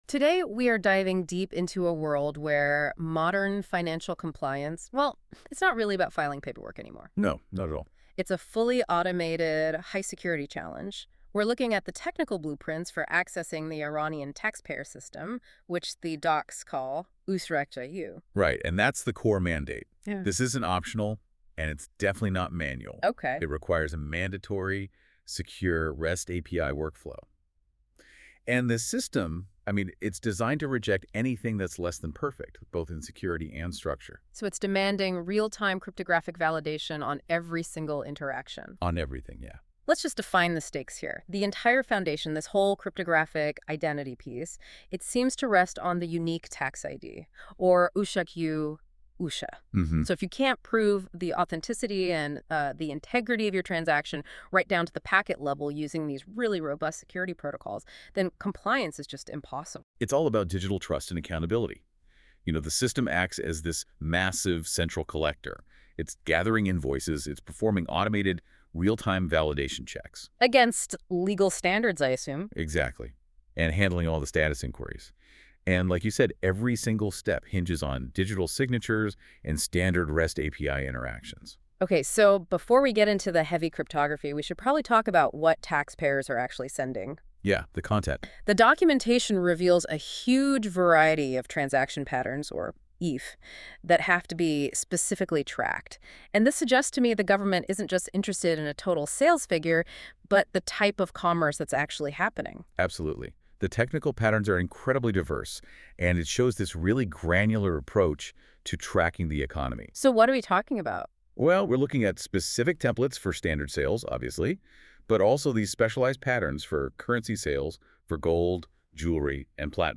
Prefer listening? I've created an audio walkthrough of the complete documentation: